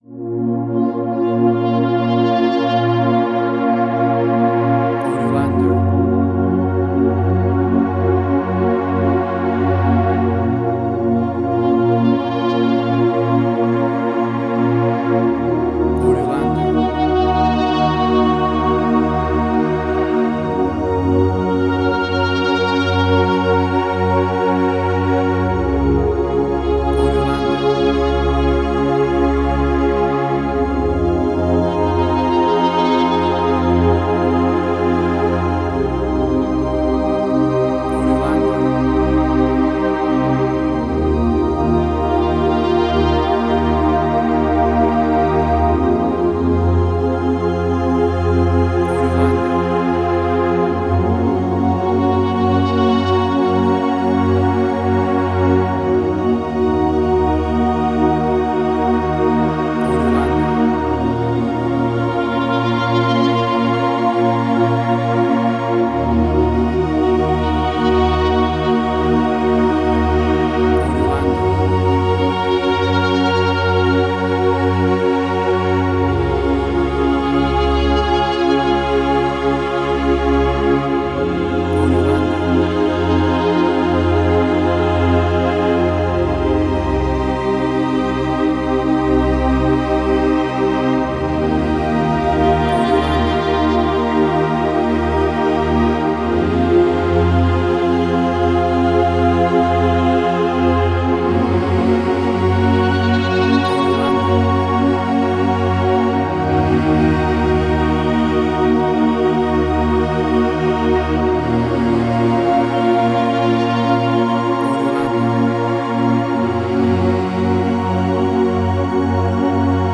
Future Retro Wave
WAV Sample Rate: 16-Bit stereo, 44.1 kHz
Tempo (BPM): 95